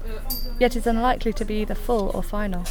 noisy_testset_wav